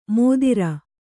♪ mōdira